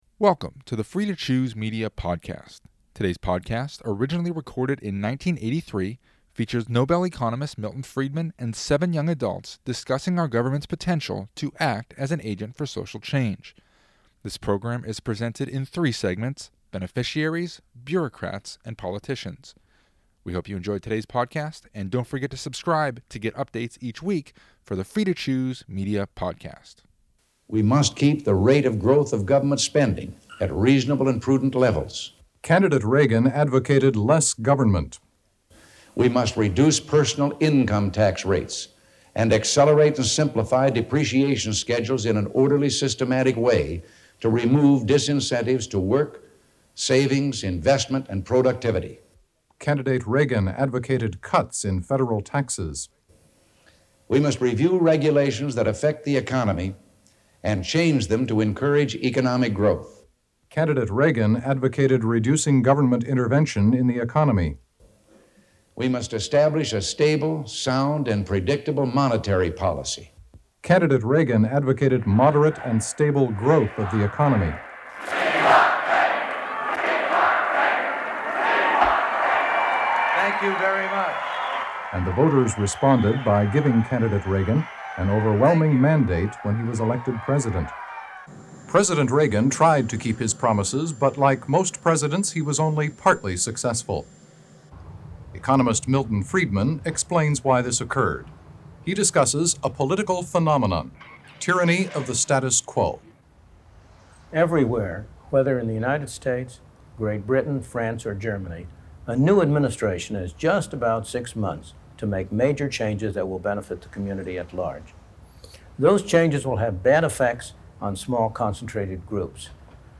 Listen to a lively discussion with Nobel Economist Milton Friedman and seven young adults discussing our government's potential to act as an agent for social change. This program is presented in three segments: Beneficiaries, Bureaucrats, and Politicians.